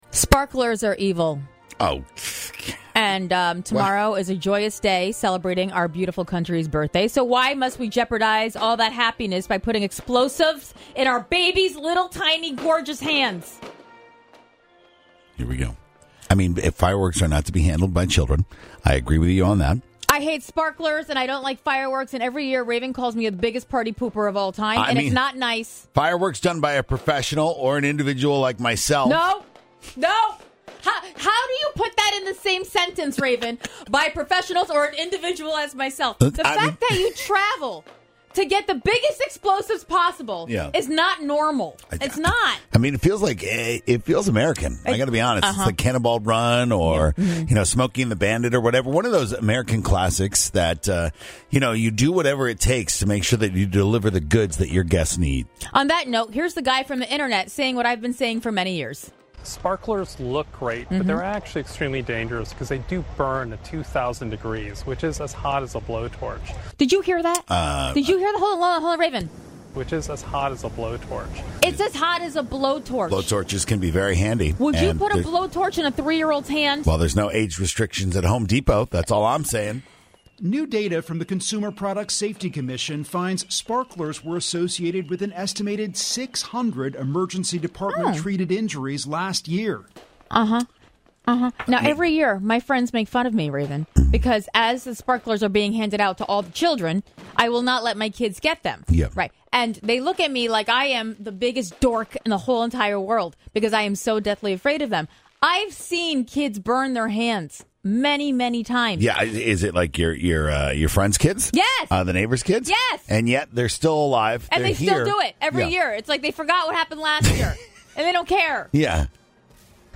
No grown person should be this mad about sparklers. If you missed this year’s rant, here ya go.